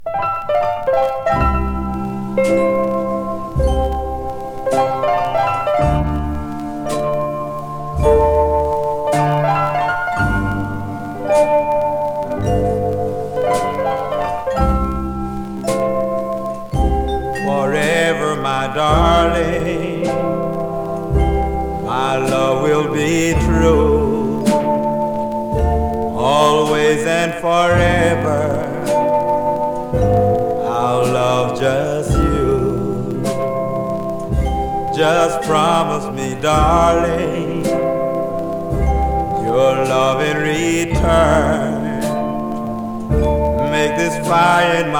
20代とは思えない貫禄ある声で、聴けば聴くほど惚れ込む素晴らしさです。
Rhythm & Blues, Ballad　UK　12inchレコード　33rpm　Mono